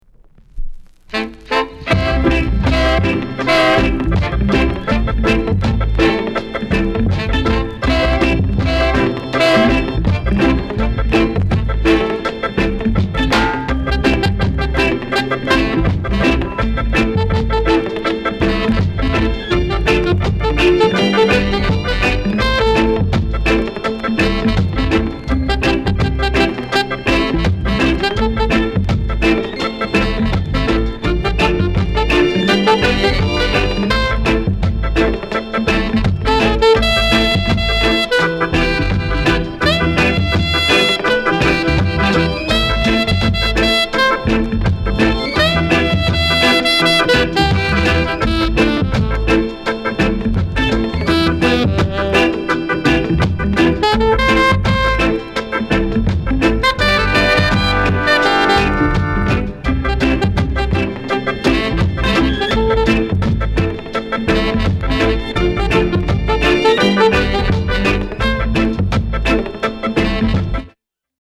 FEMALE ROCKSTEADY